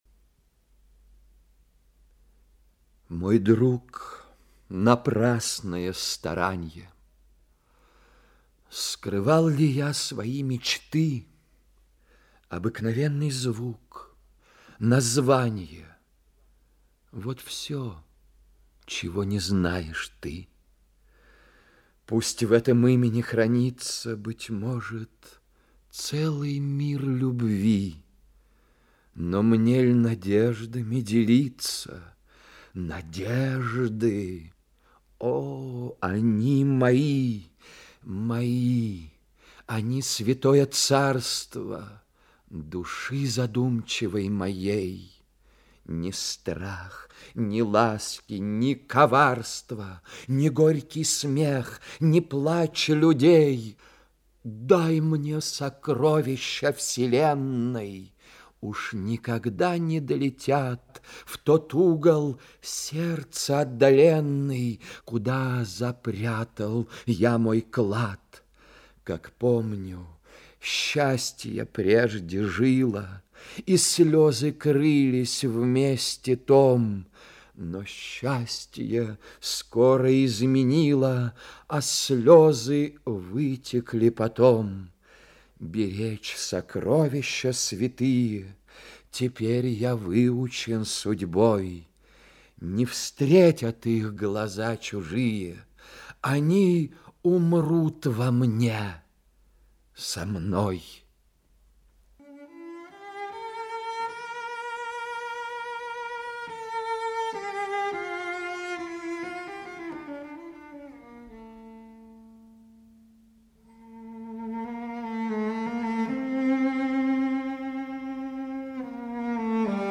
1. «М.Ю. Лермонтов – К (“Мой друг, напрасное старанье…”)(читает Геннадий Бортников)» /